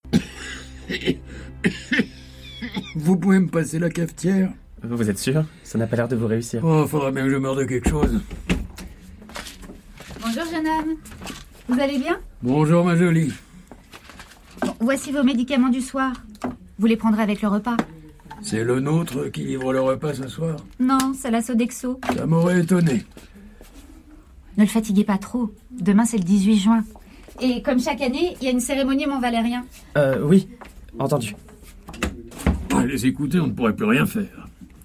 FICTION RADIOPHONIQUE (Hubert Germain / France Inter) - jeune adulte - légère - amicale